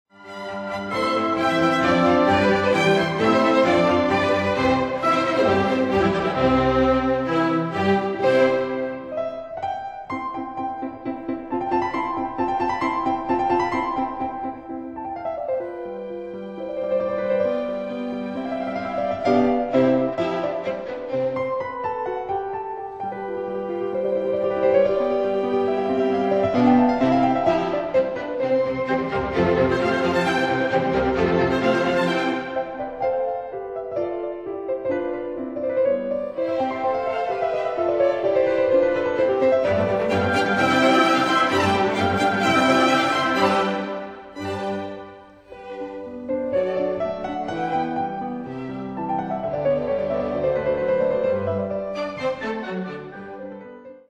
No 8, 1st Movt (piano entry)